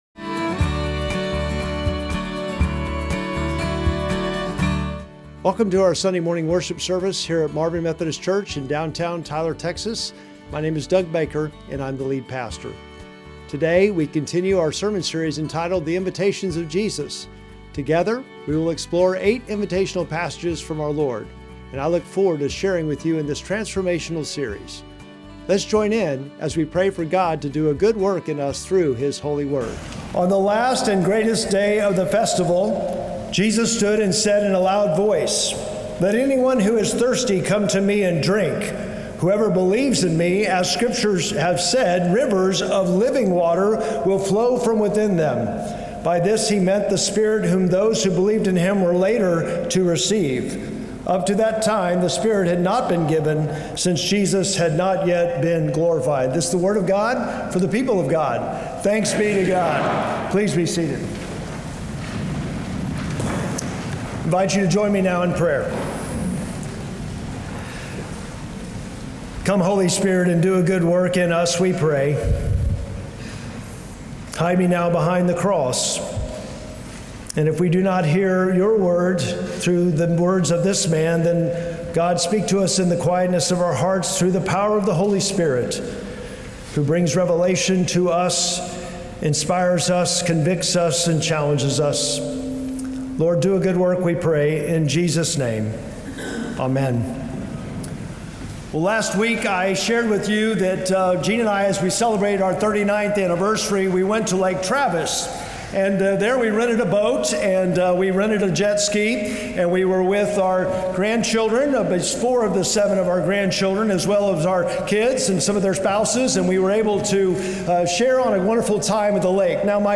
Sermon text: Mark 10:17-27